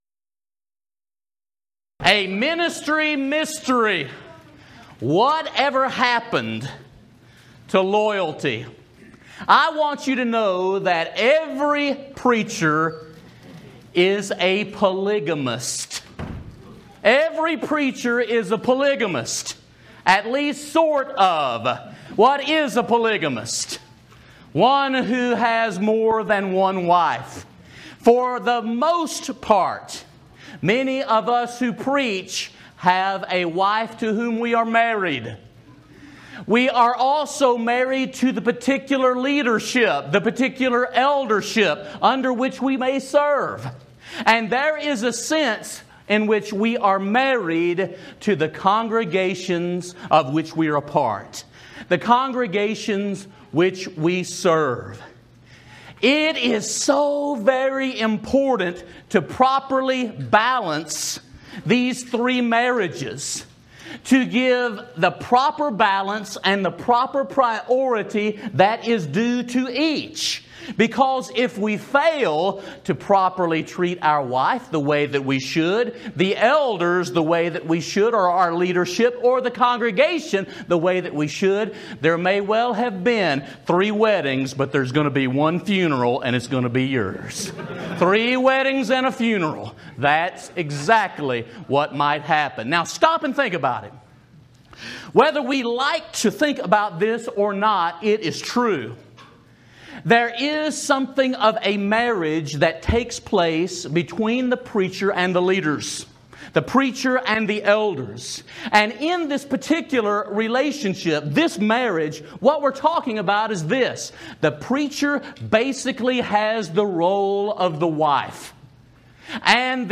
Preacher's Workshop